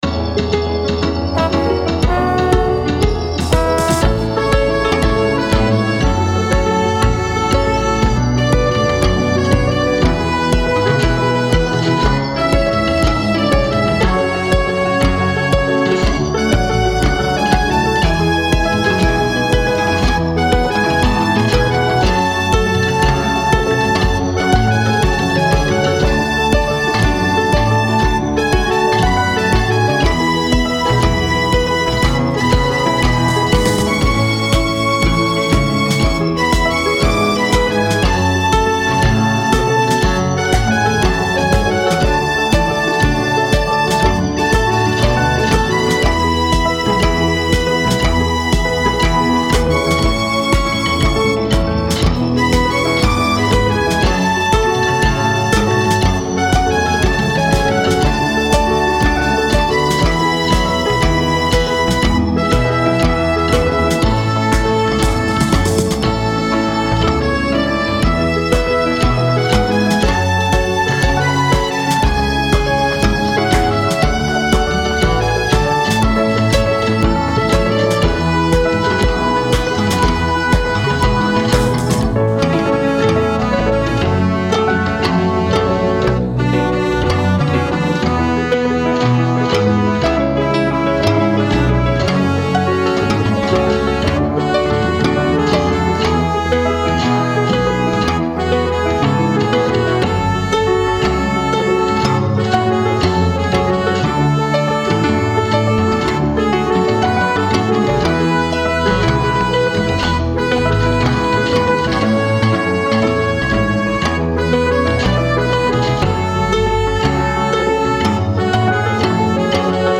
Deux versions instrumentales